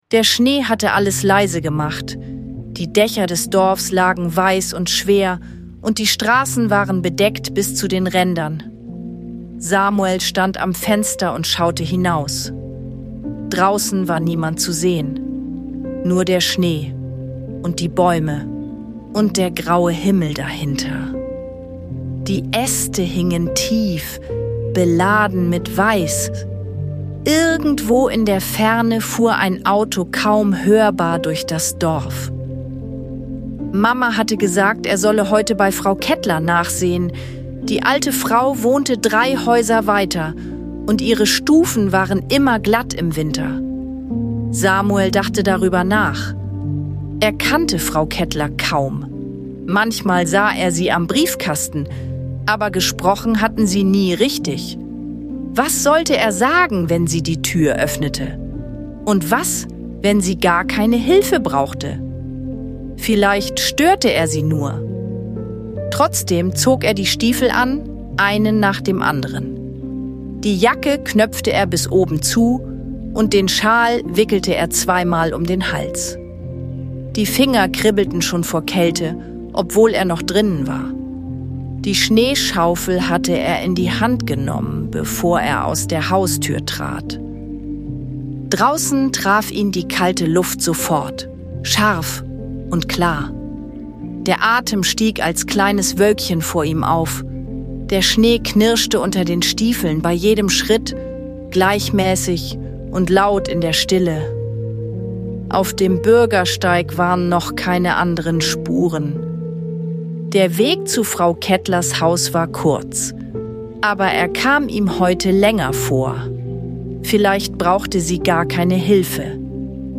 Die ruhige Atmosphäre und die sanfte Musik machen diese Folge zu einem liebevollen Begleiter für den Abend. Eine stille Geschichte zum Zuhören, Runterkommen und Einschlafen.